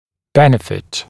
[‘benɪfɪt][‘бэнифит]польза, благо; приносить пользу, помогать; оказывать благоприятное (воз)действие; получать пользу